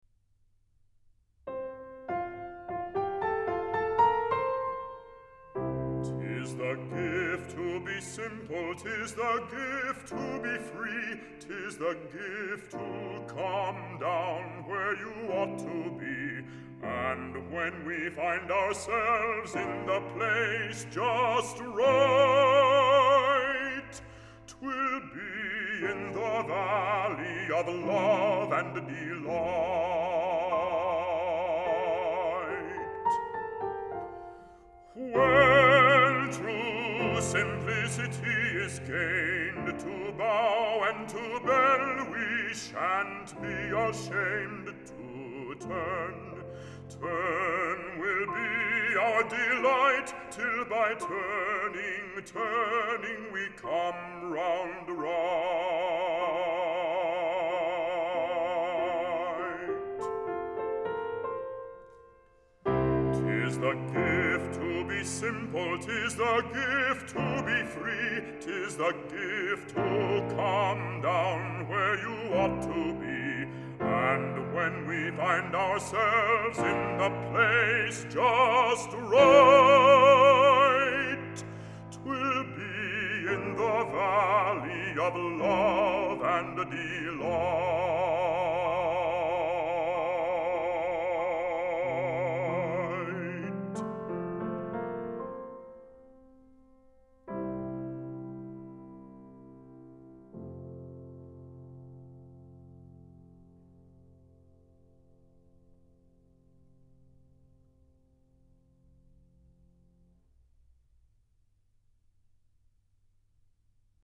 Música vocal
Canto